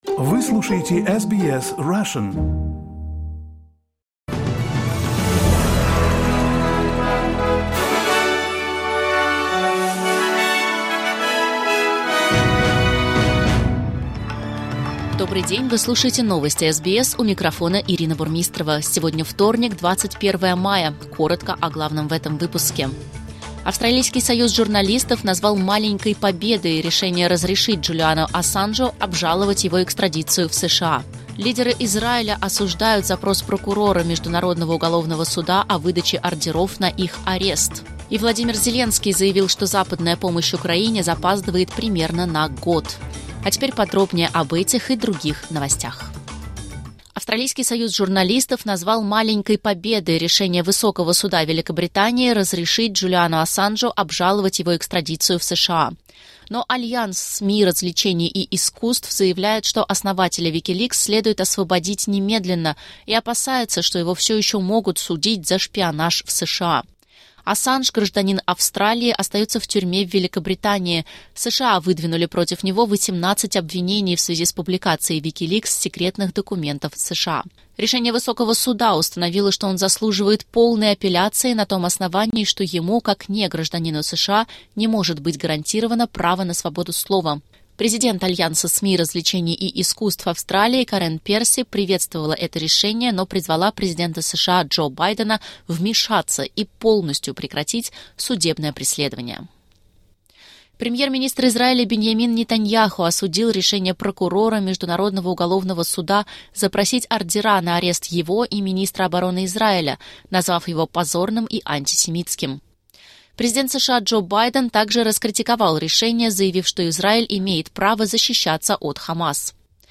SBS news in Russian — 21.05.2024